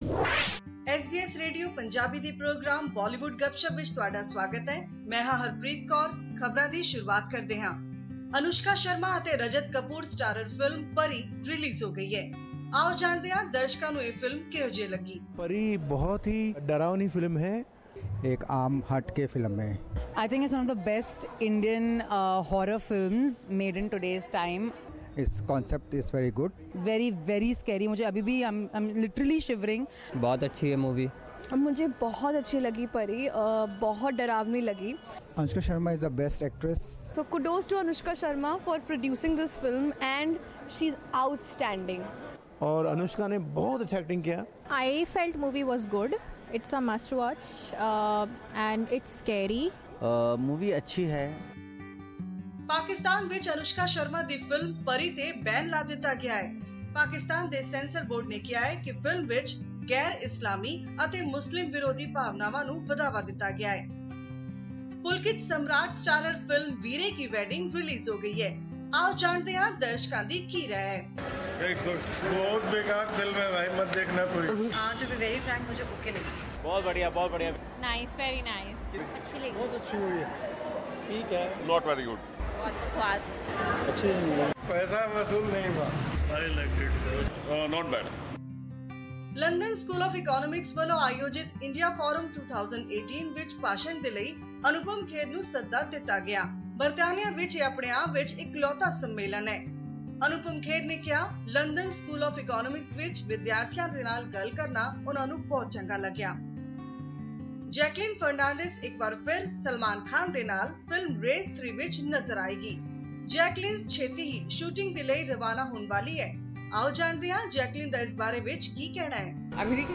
Bollywood Gupshup brings us new Bollywood releases with comments from viewers.